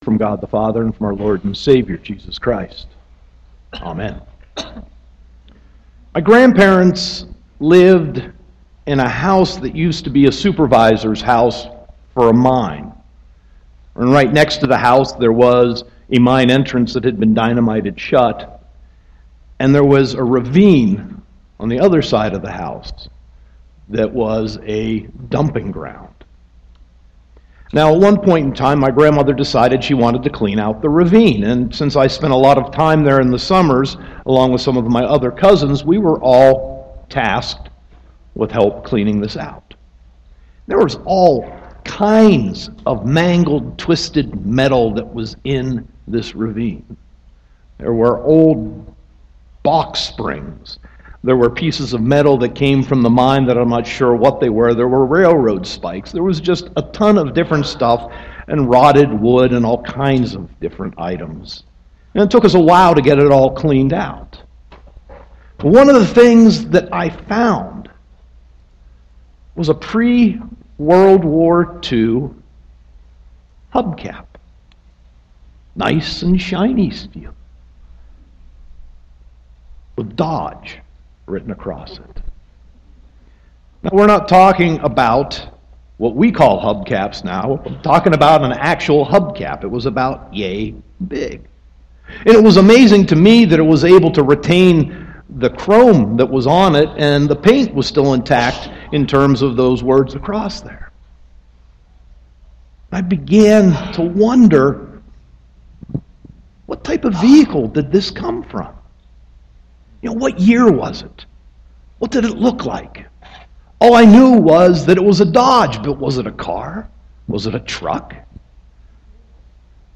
Sermon 3.6.2016